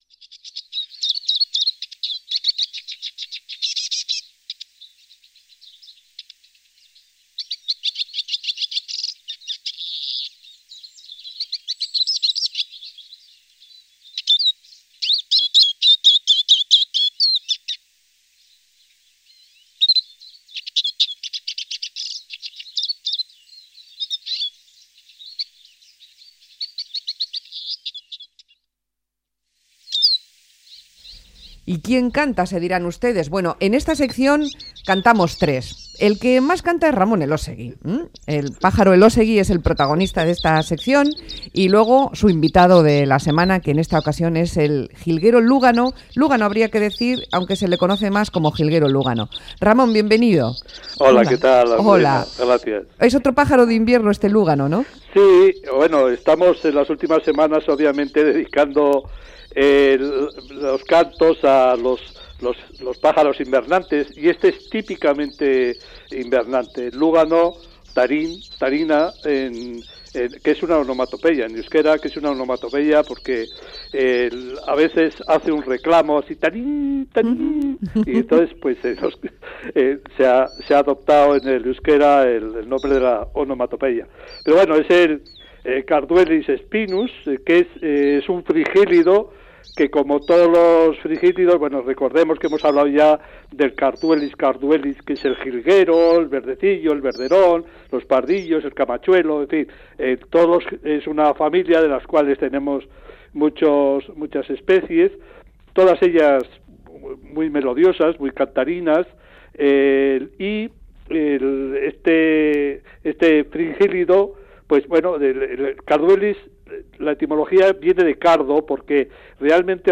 Radio Euskadi PAJAROS Juilgero lúgano Última actualización